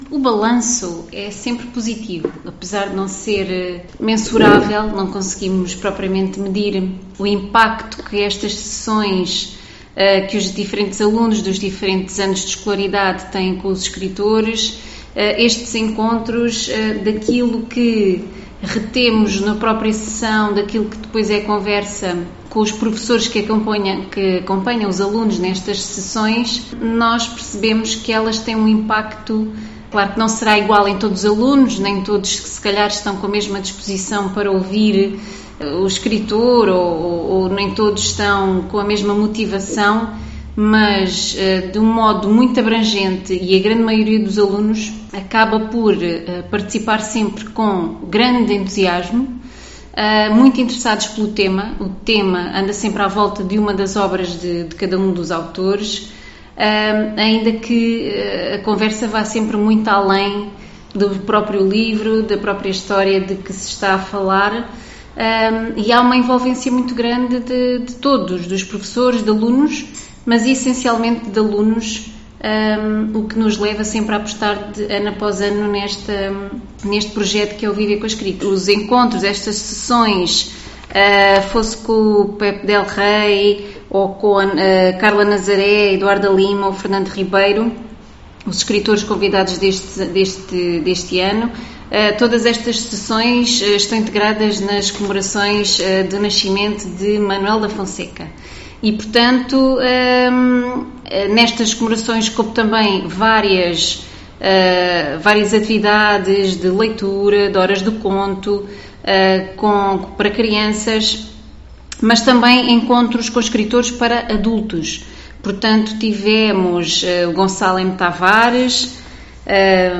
Declarações da Vereadora da Câmara Municipal de Santiago do Cacém, Sónia Gonçalves